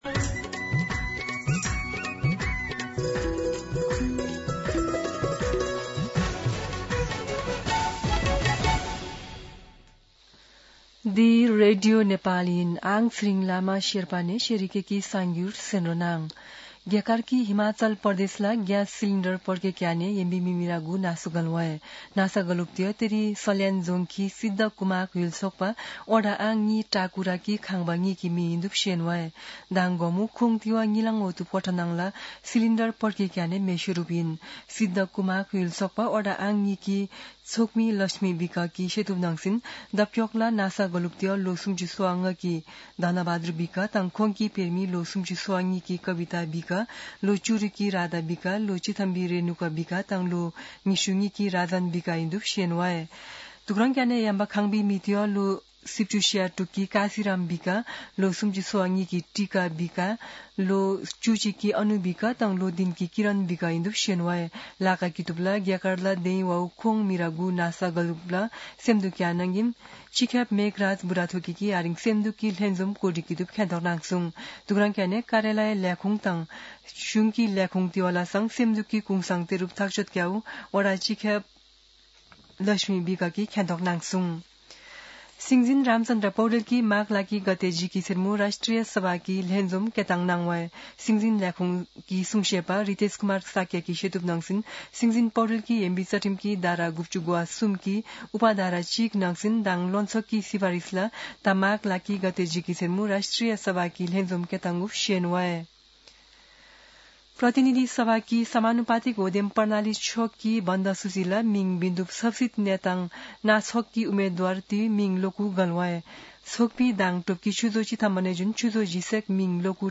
शेर्पा भाषाको समाचार : २९ पुष , २०८२
Sherpa-News-29.mp3